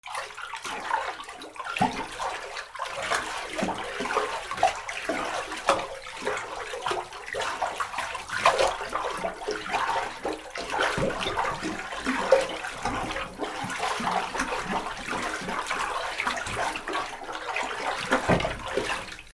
水音 水をかき回す
『バシャバシャ』